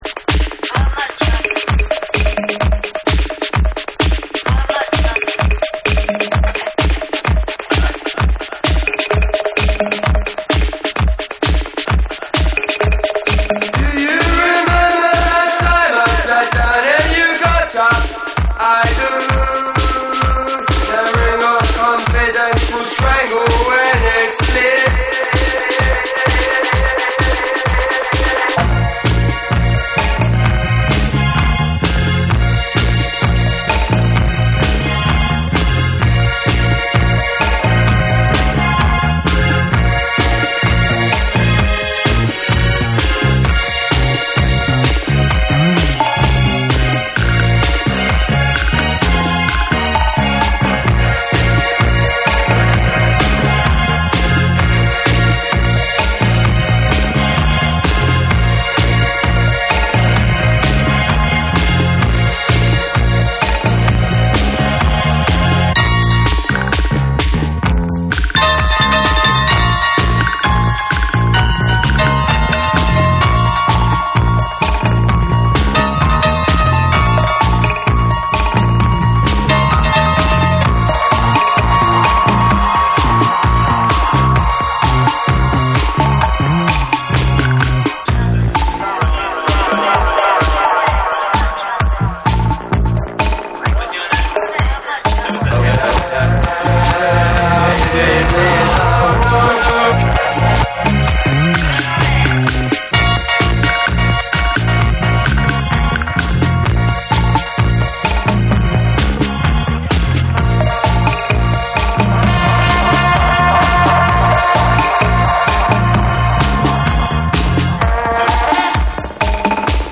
NEW WAVE & ROCK / REGGAE & DUB / RE-EDIT / MASH UP